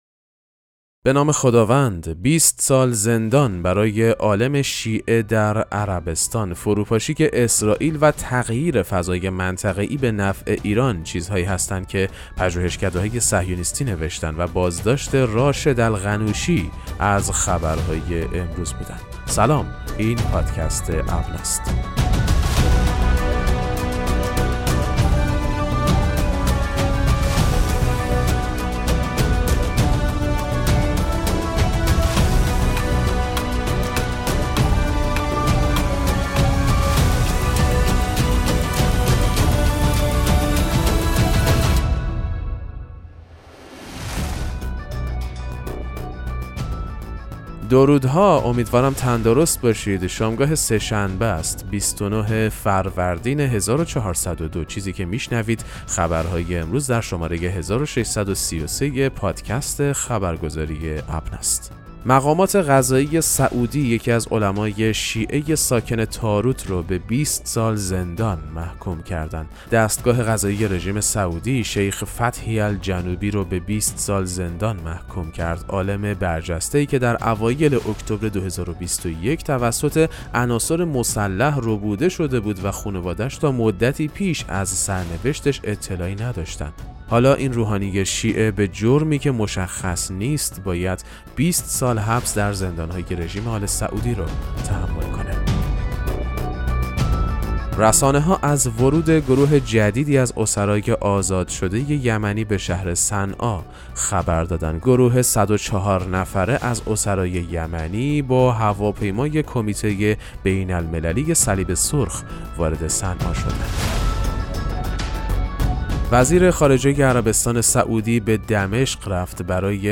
خبرگزاری اهل‌بیت(ع) ـ ابنا ـ با ارائه سرویس «پادکست مهم‌ترین اخبار» به مخاطبان خود این امکان را می‌دهد که در دقایقی کوتاه، از مهم‌ترین اخبار مرتبط با شیعیان جهان مطلع گردند. در زیر، پادکست اخبار امروز سه‌شنبه 29 فروردین 1402 را به مدت 6 دقیقه و 45 ثانیه بشنوید: